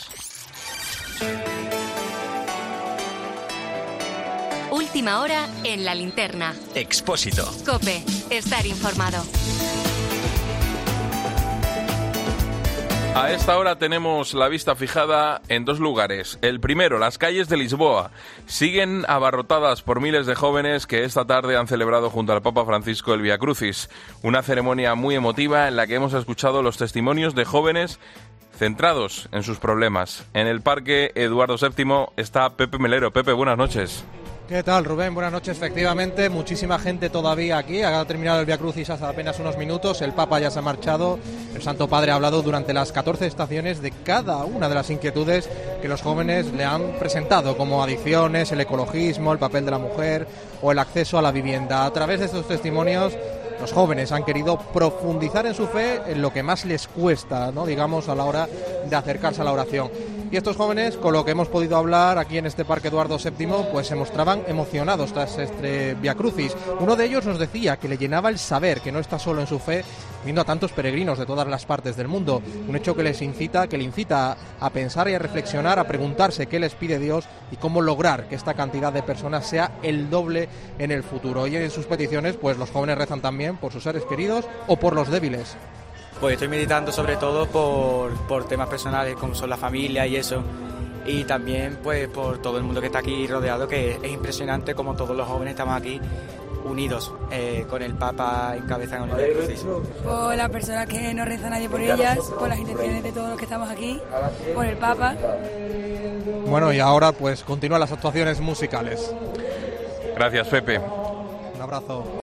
Jóvenes españoles cuentan en los micrófonos de COPE cómo han seguido el Viacrucis de la JMJ